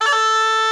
D3FLUTE83#09.wav